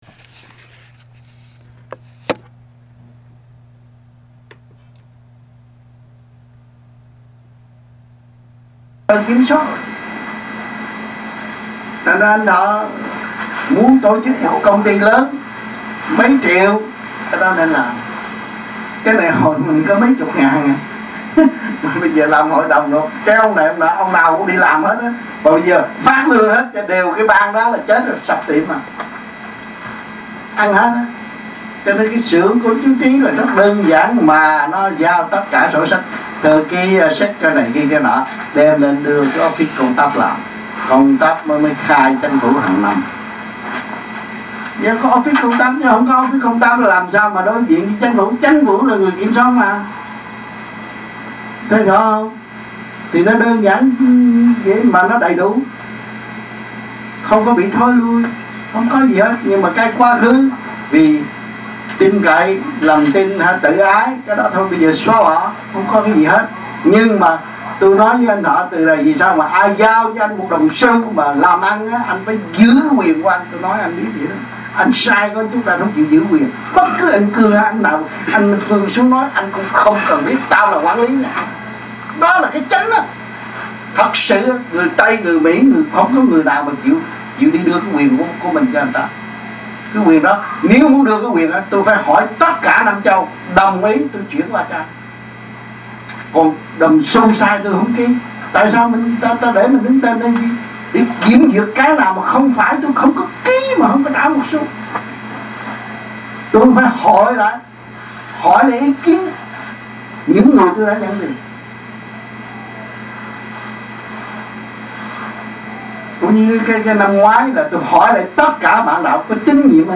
Melbourne, Australia Trong dịp : Khóa học >> wide display >> Downloads